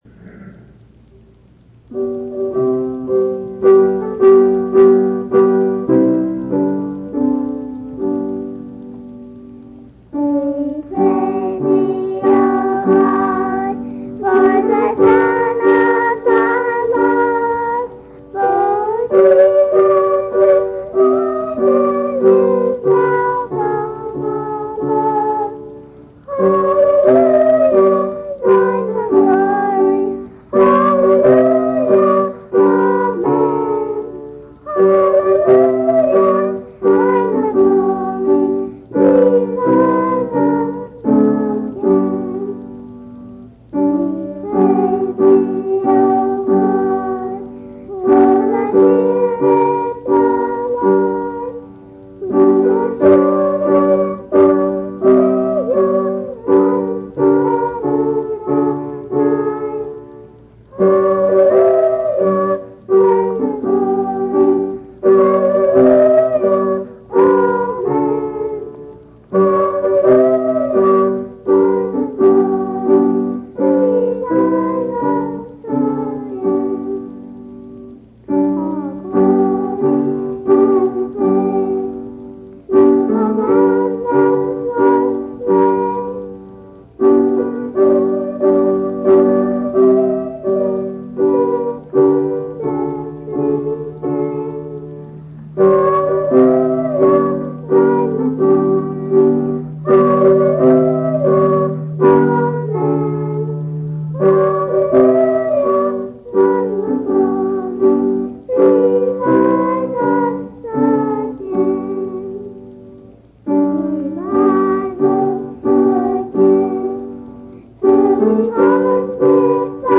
Missouri Reunion Event: Missouri Reunion